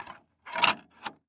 Звуки дисковода
Музыкальный диск вставлен в CD-проигрыватель